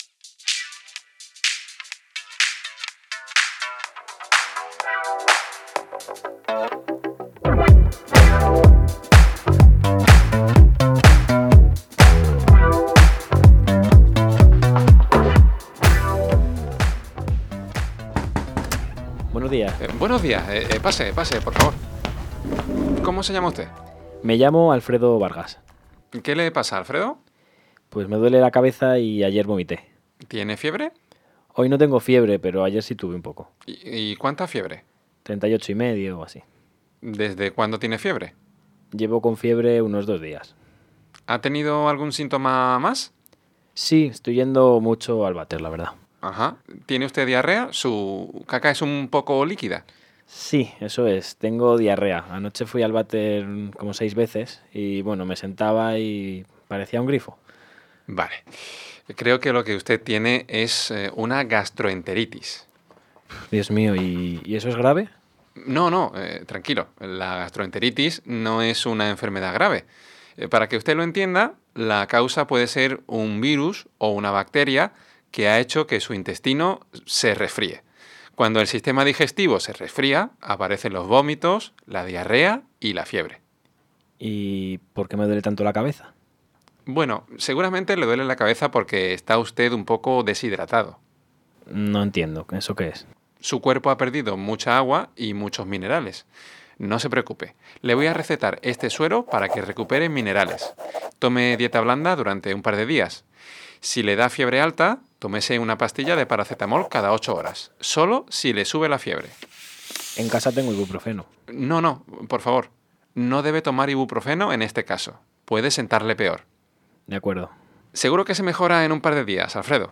• Este es un listening para aprender español, nivel básico. La historia dura 2 minutos; el resto son preguntas y otros datos.